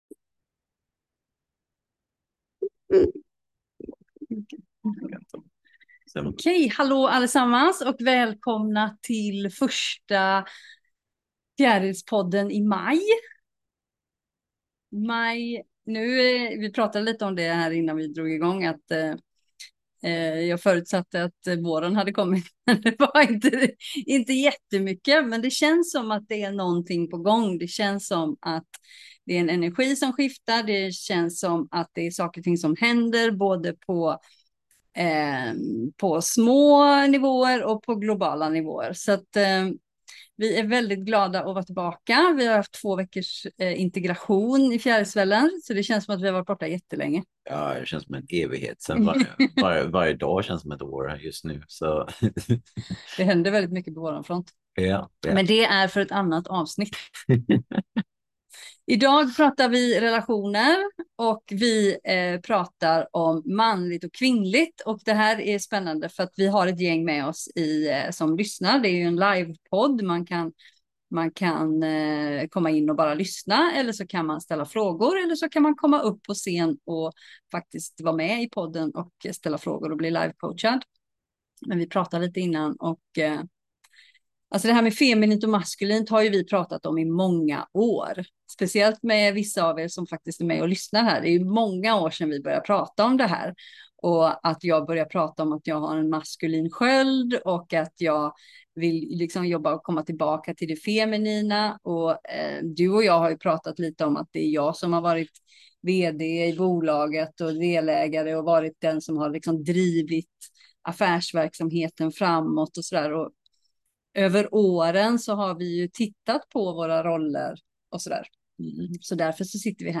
Den här veckan bjuder vi in till ett live-samtal som rör vid något djupt mänskligt: balansen mellan det feminina och det maskulina, både i oss själva och i våra relationer.